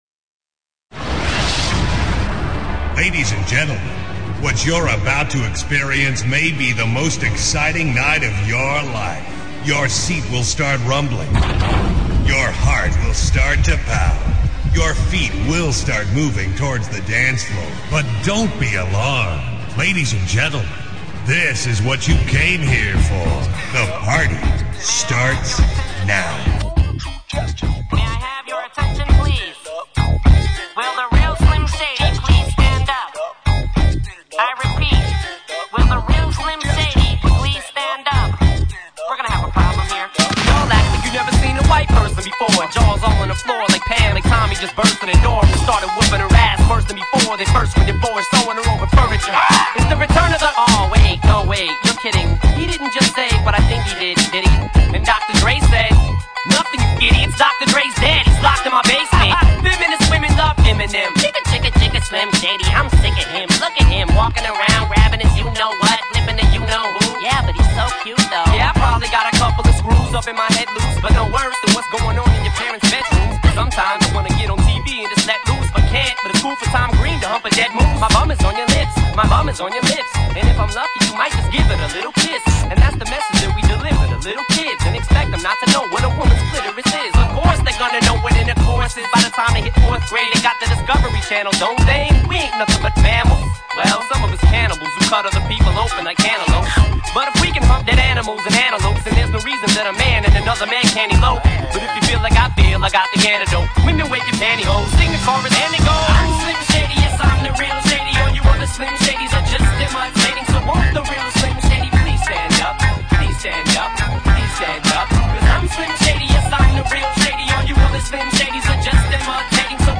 Goes PuRe Punjabi